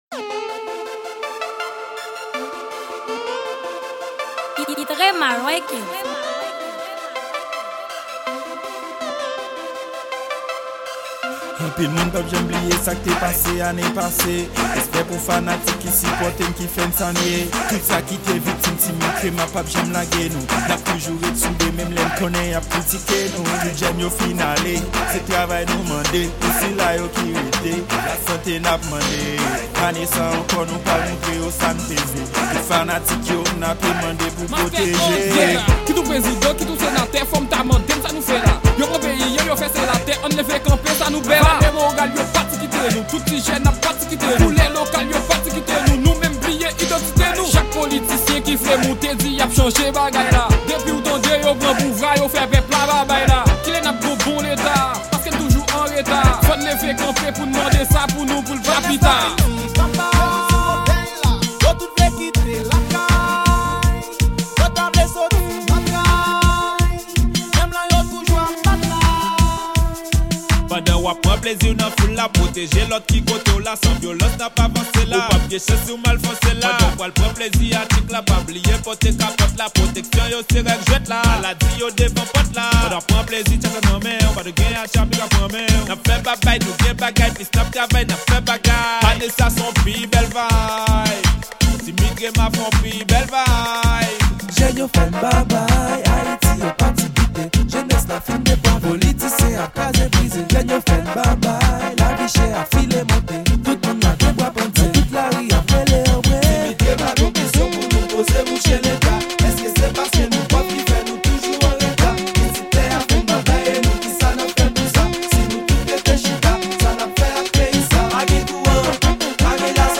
Genre : KANAVAL